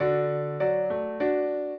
piano
minuet0-11.wav